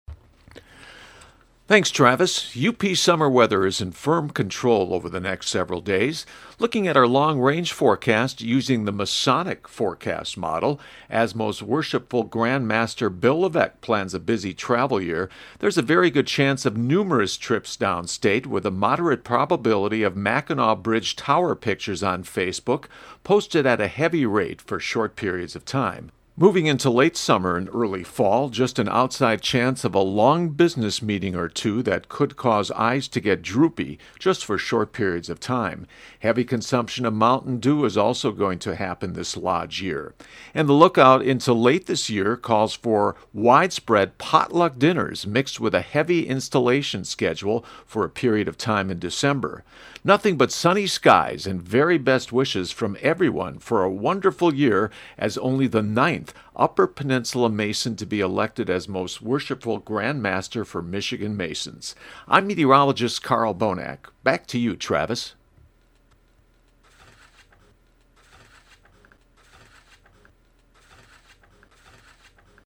The Weather Forecast of course....